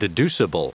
Prononciation du mot deducible en anglais (fichier audio)
Prononciation du mot : deducible